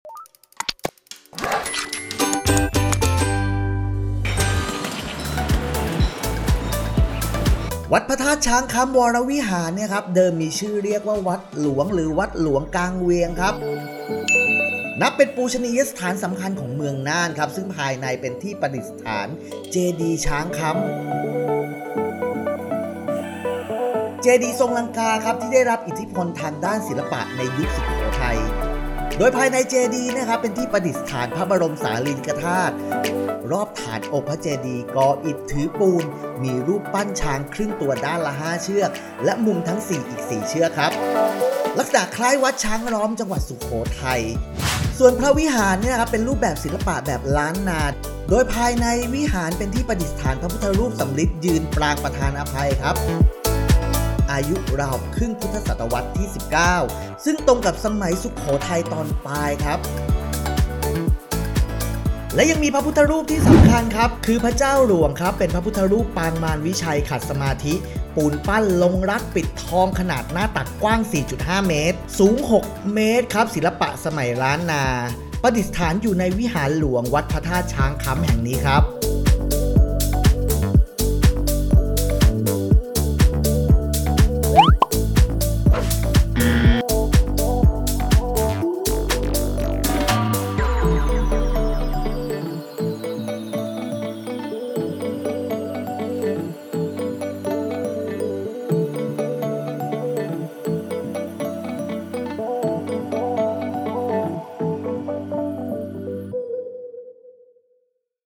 เสียงบรรยายภาพ